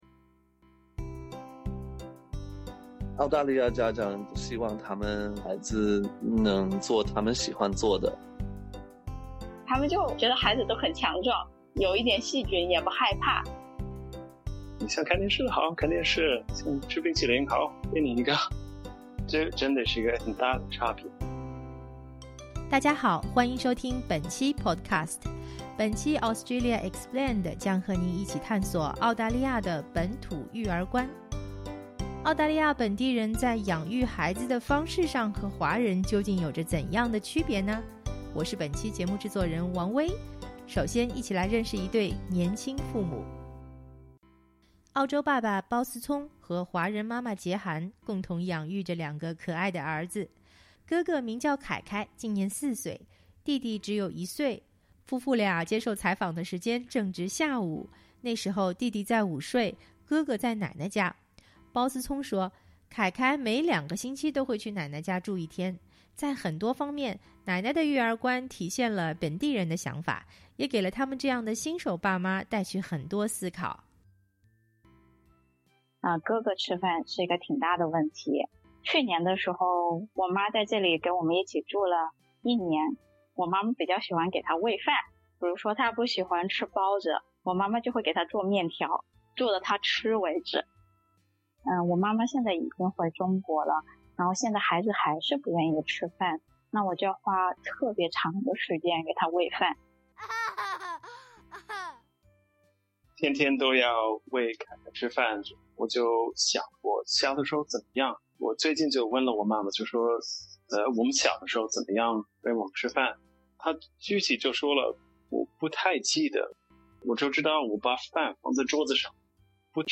夫妇俩接受SBS普通话采访的时间正值下午。